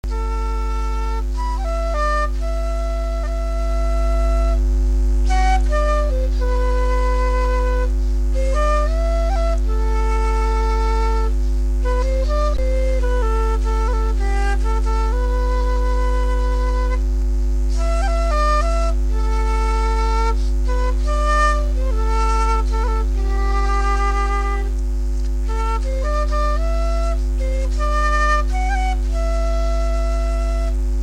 Mélodie bretonne
Résumé instrumental
Pièce musicale inédite